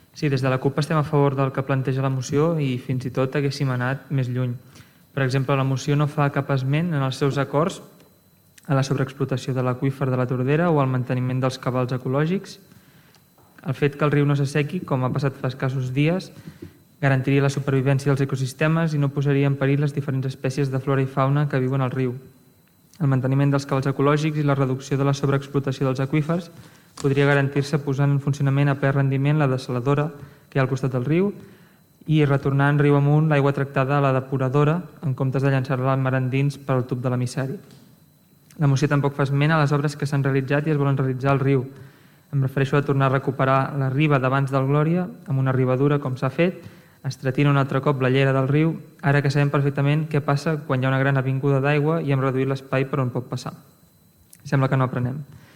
Aquest és un dels acords al qual s’ha arribat al ple de l’Ajuntament de Tordera.
Des de la CUP, Oriol Serra es mostrava a favor de la moció. Tot i així, destaquen que la moció oblida certs punts importants com la sobreexplotació del cabal de La Tordera: